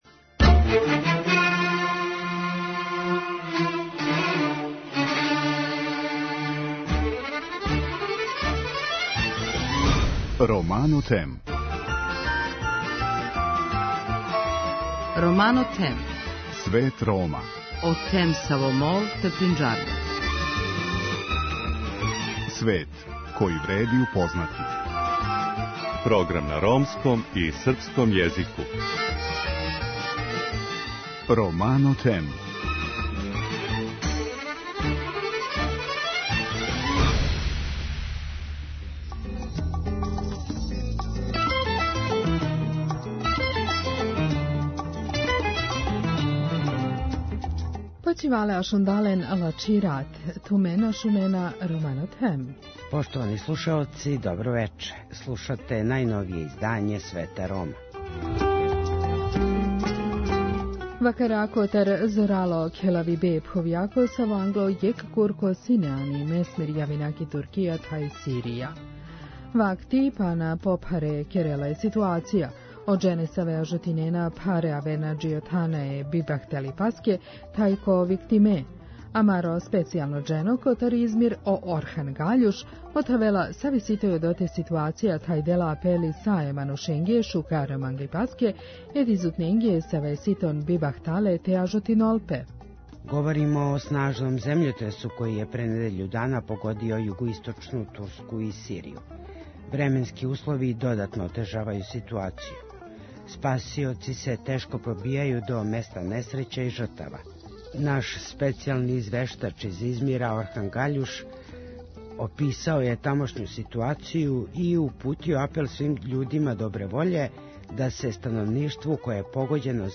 Емисија свакодневно доноси најважније вести из земље и света на ромском и српском језику.